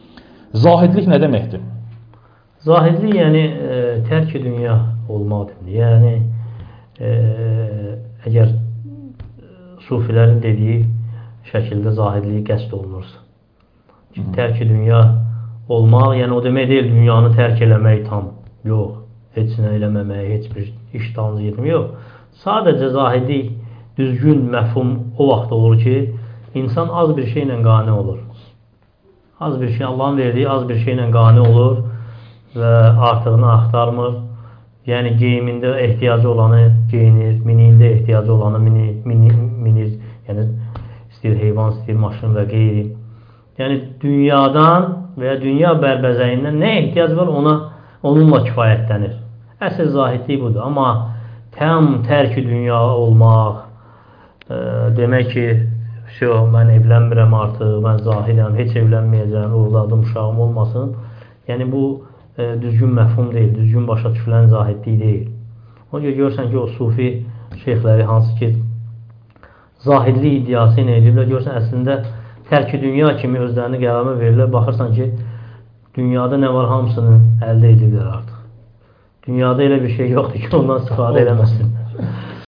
Sual-cavab (mövzularla)